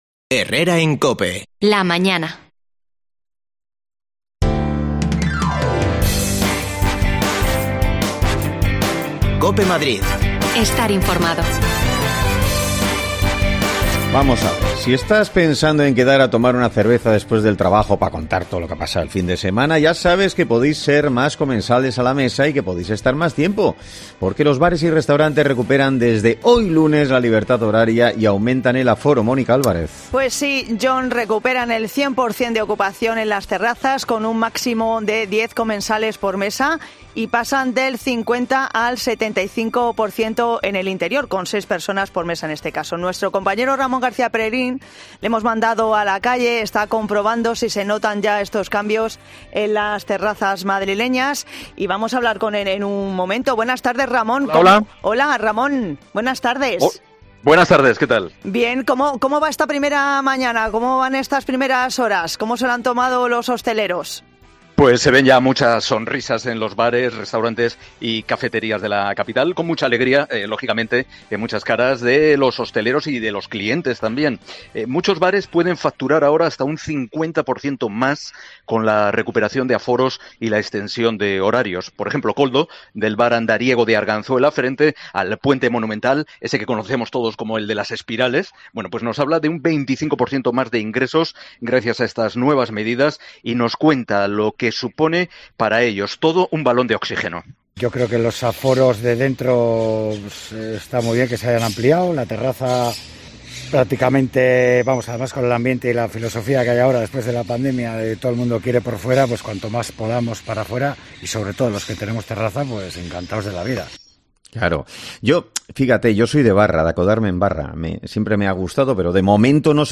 AUDIO: Los bares y restaurantes vuelven hoy casi a la normalidad. Salimos a la calle para saber cómo están viviendo estas primeras horas,...